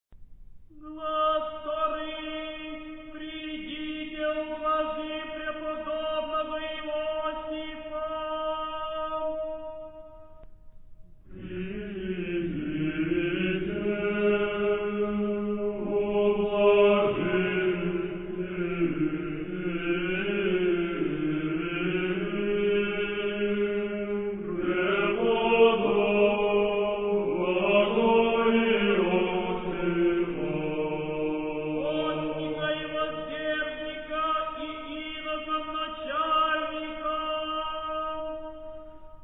それはカノナルクの歌い方と呼ばれるもので、ソロの歌い手が祈祷書をみながら文を一行ずつ棒読みでまっすぐに唱え、同じ歌を聖歌隊や会衆全体が同じ歌詞にメロディをつけて歌います。
カノナルクと歌う　「ヴォロコラムスクの聖イオシフのスティヒラ」ズナメニイ